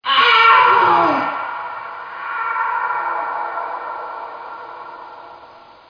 1 channel
scream2.mp3